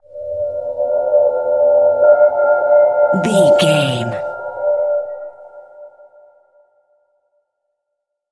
Short musical SFX for videos and games.,
Sound Effects
Ionian/Major
aggressive
epic
intense
energetic
funky